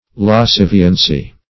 Search Result for " lasciviency" : The Collaborative International Dictionary of English v.0.48: lasciviency \las*civ"i*en*cy\ (l[a^]s*s[i^]v"[i^]*en*s[y^]), n. [See Lascivient .] Lasciviousness; wantonness.